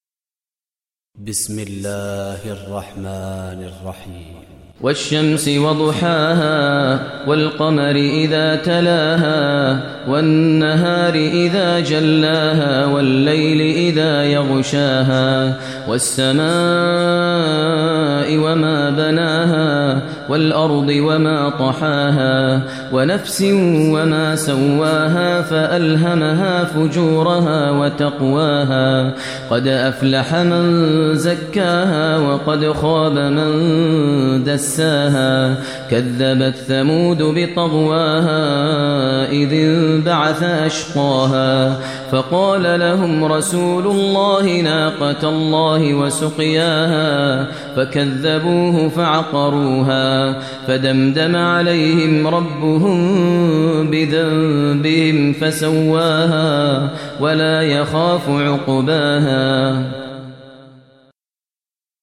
Surah Shams Recitation by Maher al Mueaqly
Surah Shams, listen online mp3 tilawat / recitation in Arabic, recited by Imam e Kaaba Sheikh Maher al Mueaqly.